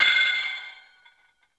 METL.P SPARK.wav